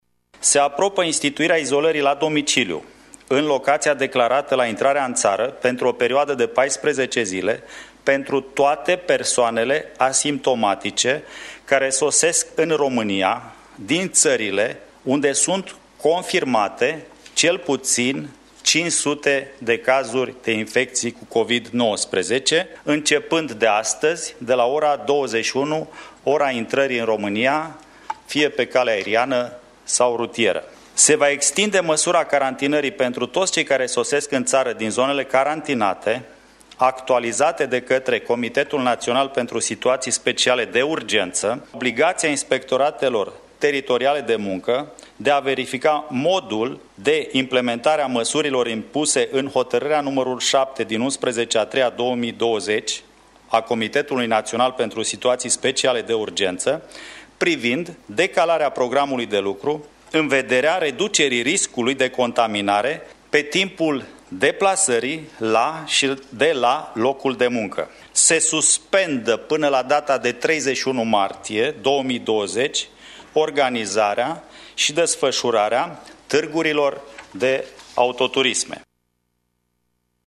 Potrivit ministrului de interne, Marcel Vela, se va extinde măsura carantinării pentru toţi cei care sosesc în ţară din zonele carantinate: